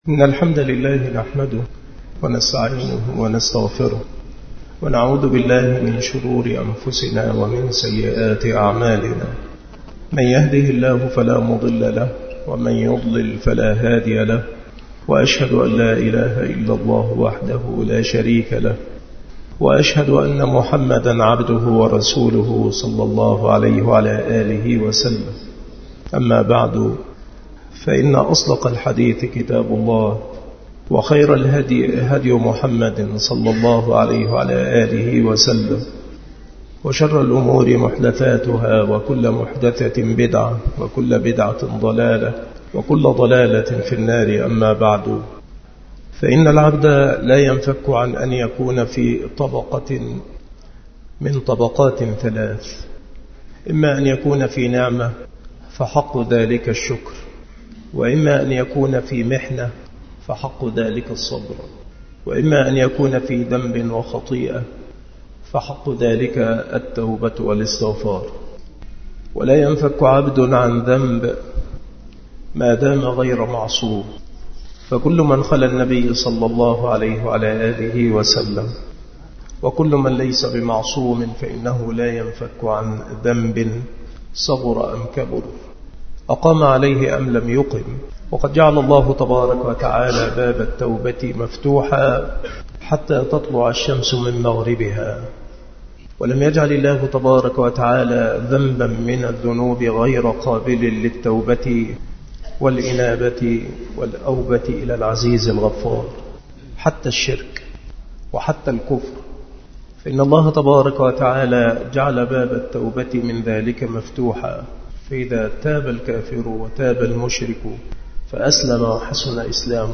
المحاضرة
مكان إلقاء هذه المحاضرة بمسجد أولاد غانم بمدينة منوف - محافظة المنوفية - مصر